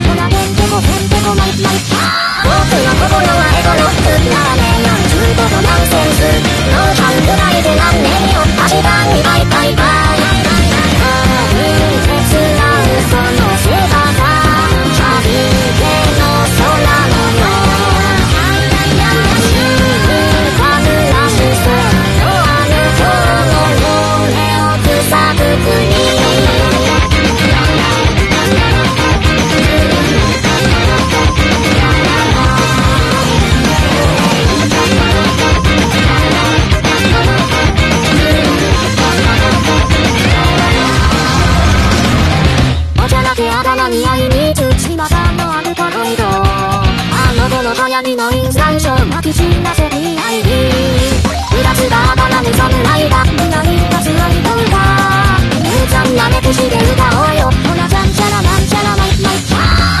esta es la version cantada